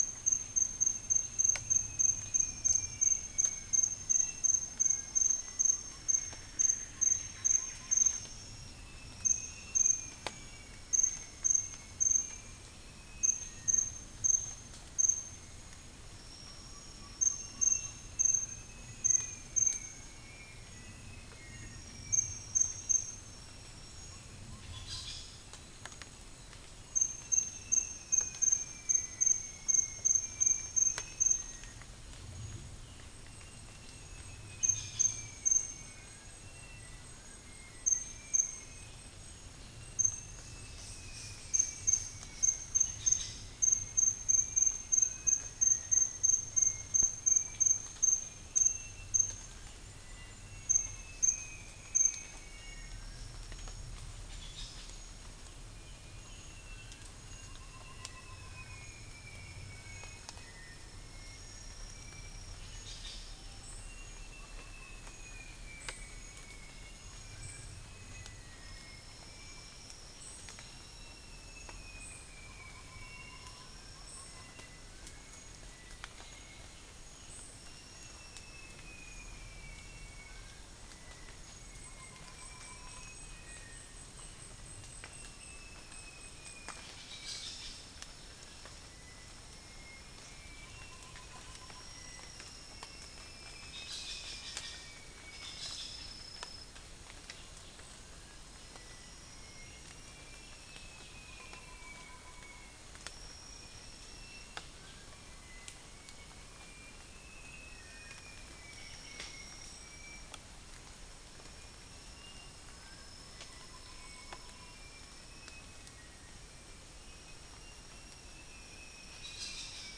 Berbak NP
Cyanoderma erythropterum
Pelargopsis capensis
Macronus ptilosus
Aegithina viridissima
Trichastoma malaccense
Malacopteron affine
unknown bird
Dicaeum cruentatum
Arachnothera hypogrammica
Dicaeum trigonostigma